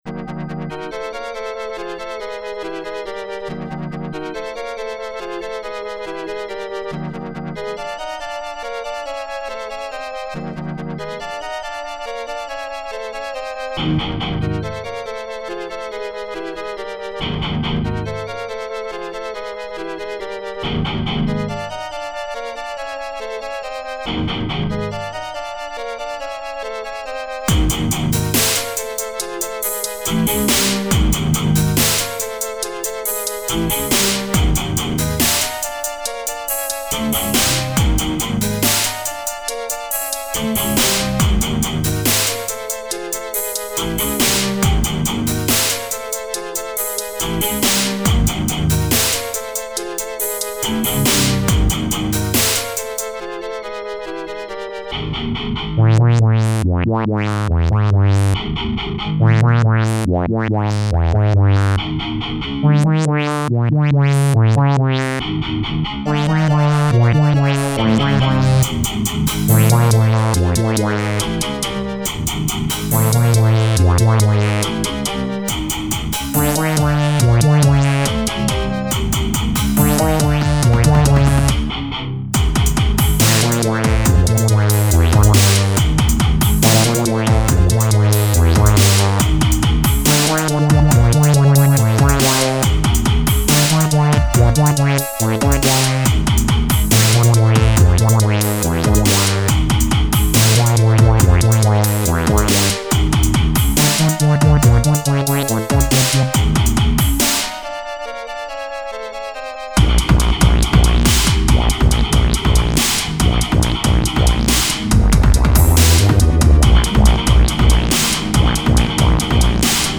Heavy Metal Dub Step?
here is a metal influenced tune